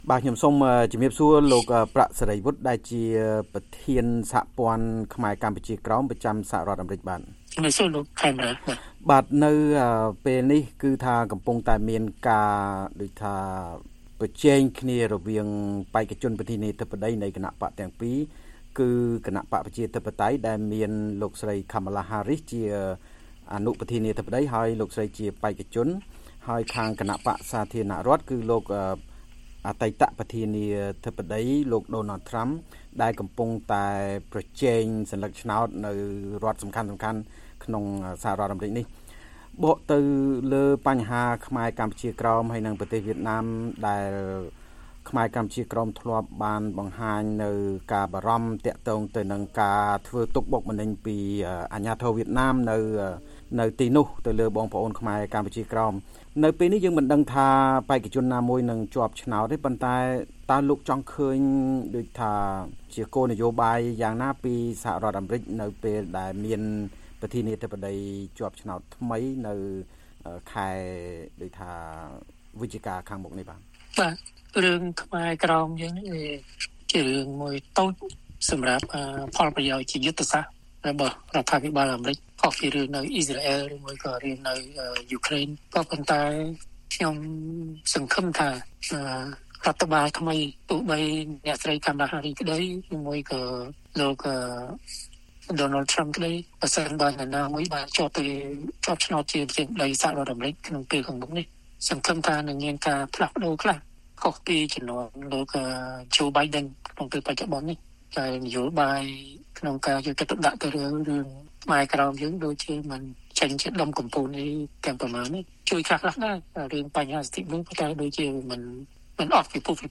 សូមស្តាប់ស្តាប់កិច្ចសម្ភាសន៍ទាំងស្រុងដូចតទៅ៖